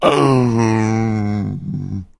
fracture_die_3.ogg